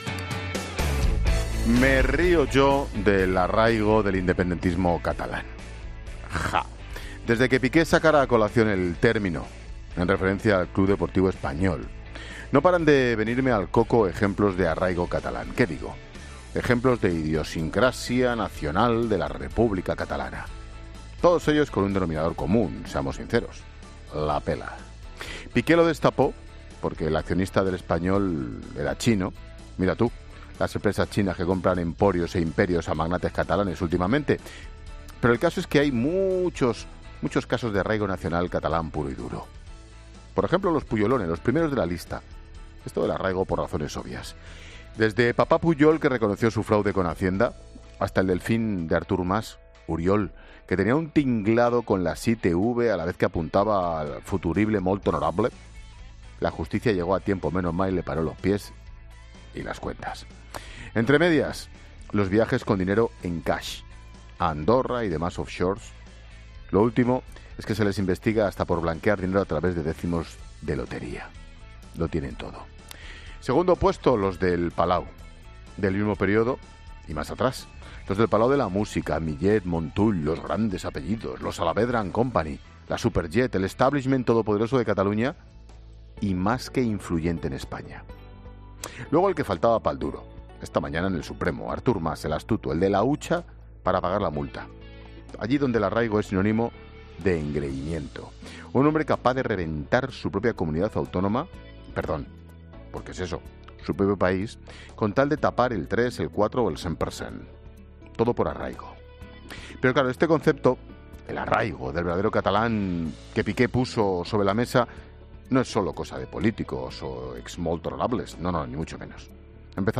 AUDIO: El comentario de Ángel Expósito.
Monólogo de Expósito